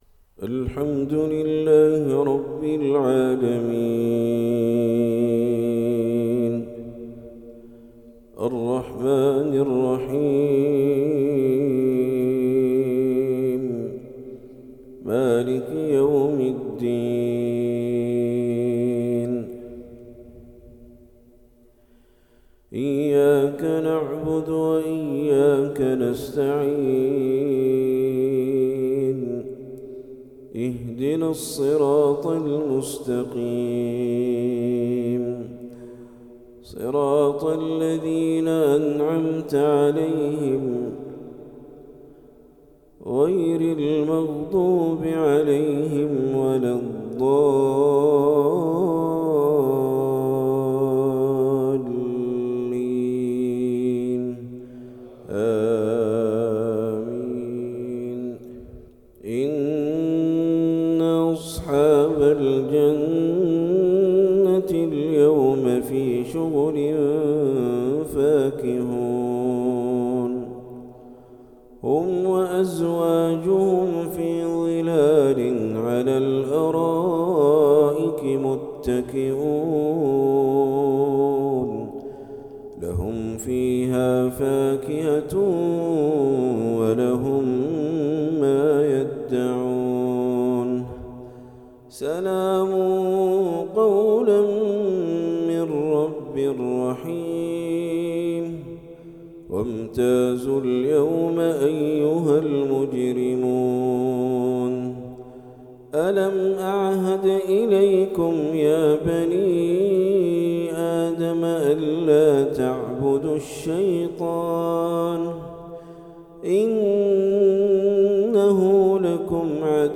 تلاوة رائعة
سورة يس - جامع الرجحي بالرياض